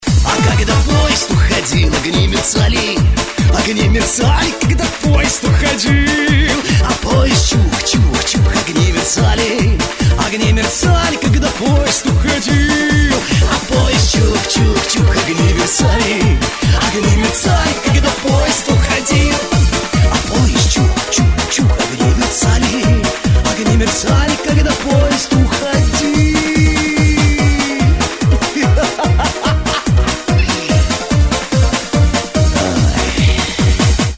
• Качество: 192, Stereo
шансон
эстрадные
Стиль: Шансон